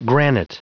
Prononciation du mot granite en anglais (fichier audio)
Prononciation du mot : granite